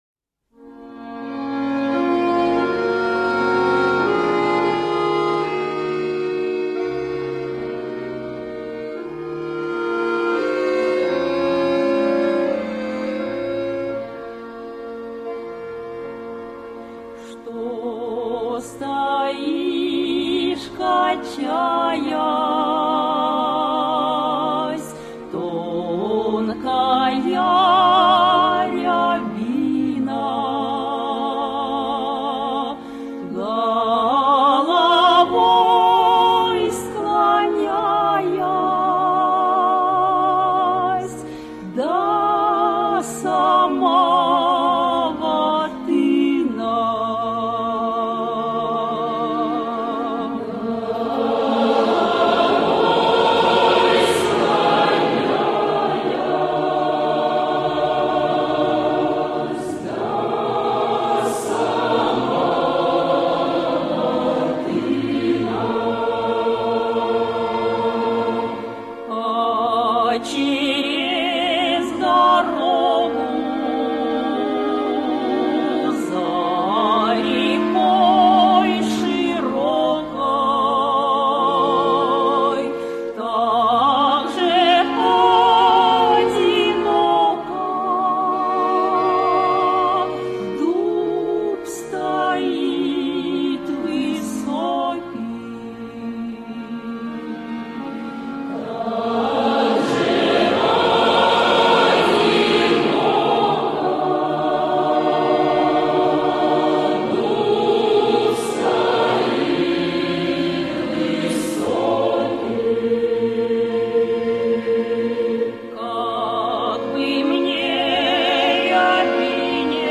песни